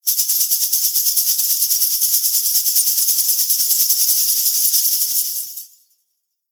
Danza árabe, bailarina haciendo el movimiento shimmi 02
continuo
moneda
Sonidos: Acciones humanas